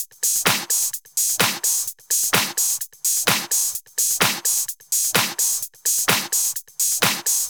VFH1 128BPM Moonpatrol Kit 4.wav